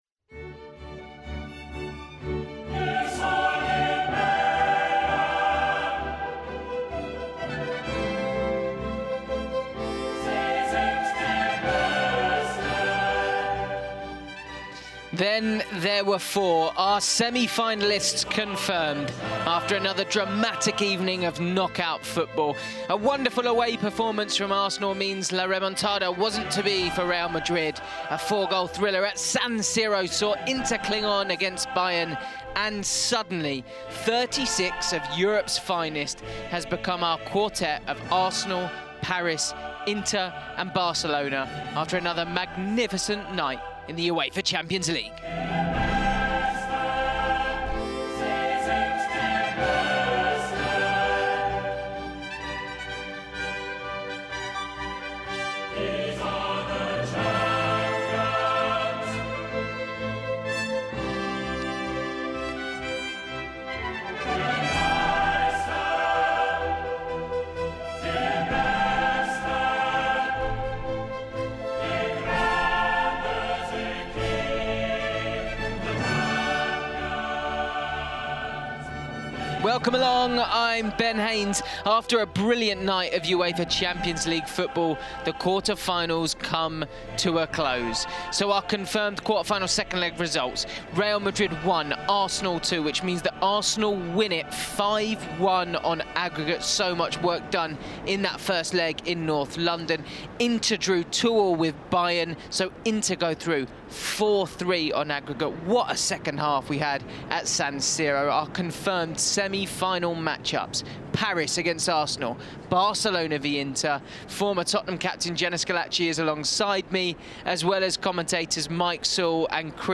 We hear from Arsenal’s Player of the Match Declan Rice and manager Mikel Arteta, plus Bayern’s Harry Kane. And we look ahead to both semi-finals, Inter v Barcelona and Arsenal v Paris.